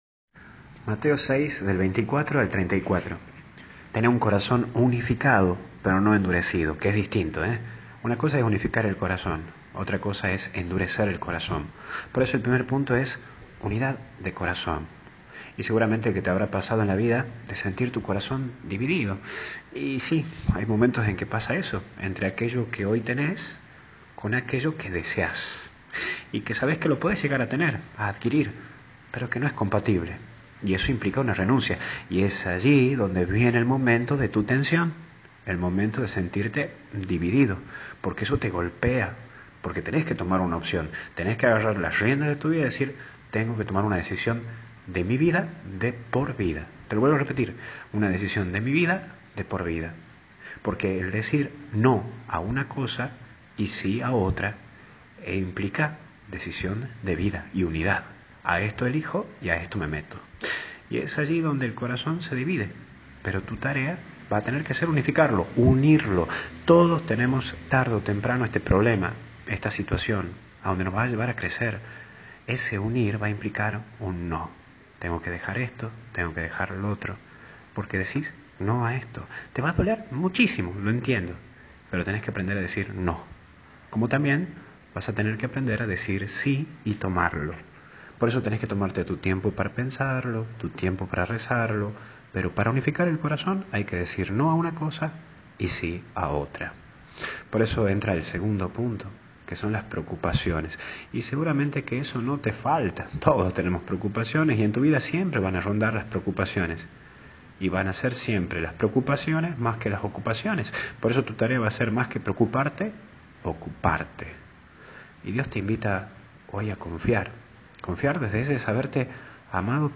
Meditación Diaria